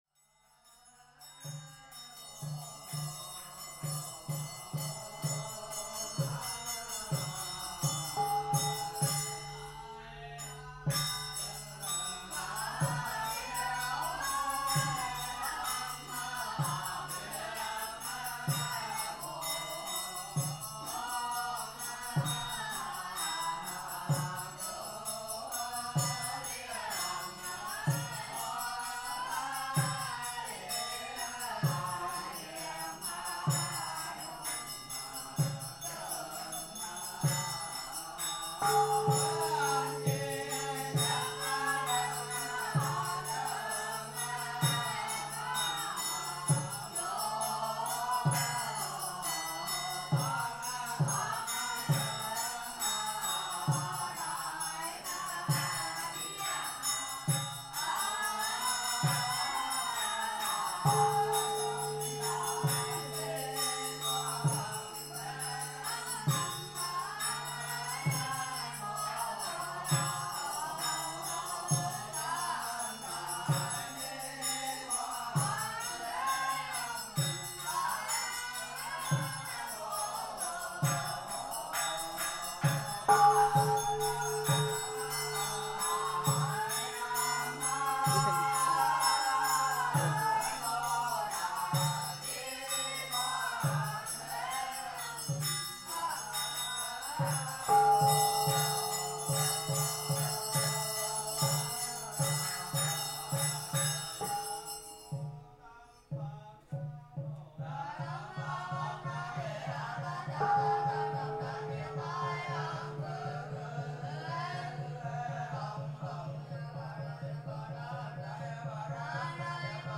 Daily Buddhist chanting
This recording captures Buddhist monks chanting at the Tu Hieu Pagoda, a Nguyen Dynasty-era Buddhist temple in Hue, Vietnam.